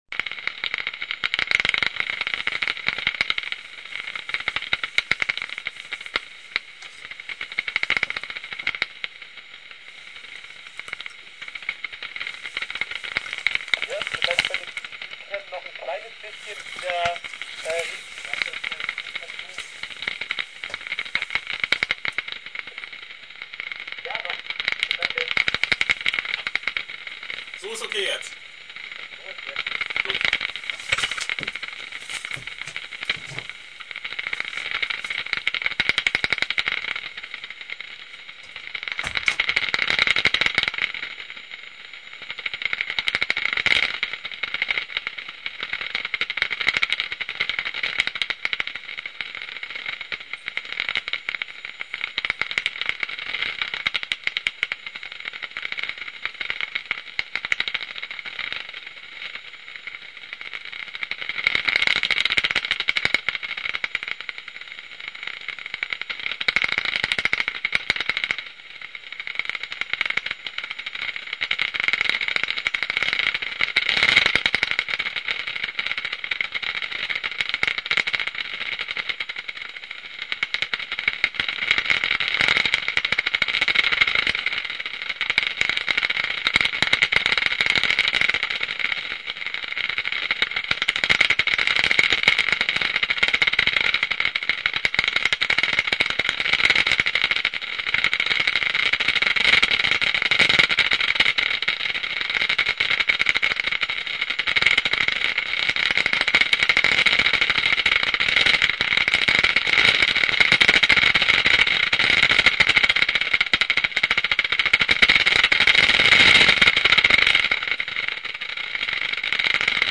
The sound picture shows a self-organizing aesthetics of chaos and coincidence as the interfering of hundreds of animals leaves a fine weaved sound pattern.
01 Fledermausschwarm | cutting:
Bad Bramstedt / 2004
As the sounds of bats, their sonar rendered audible to human ears, sounds a bit like several people playing Yahtzee!, shaking dice in those little plastic cups, but never actually rolling them, just shaking and shaking. The percussive rattle fluctuates from baseball card in the spokes flutter to a dense clattery cacophony, a tangled rhythmic web that is constantly shifting and undulating, and is in its own weird way quite soothing and hypnotic.